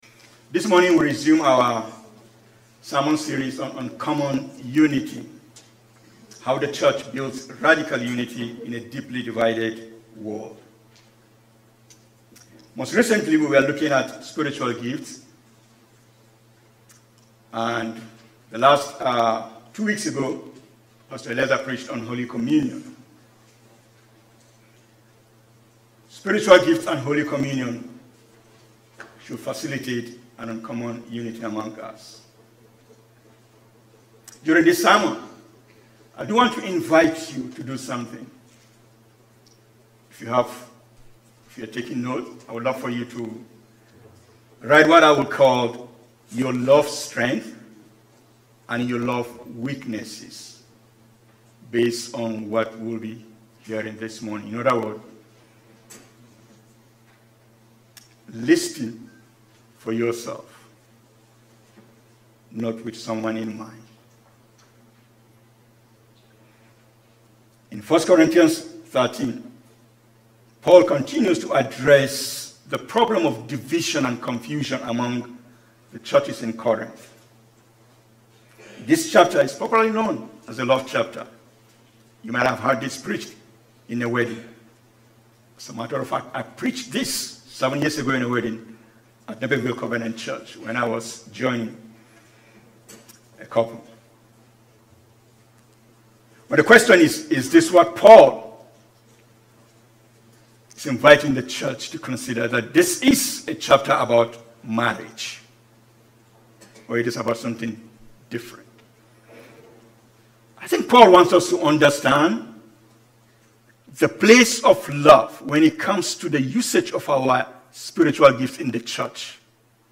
Sermons | Faith Covenant Church